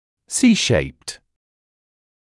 [‘siːʃeɪpt][‘сиːшэйпт]С-образный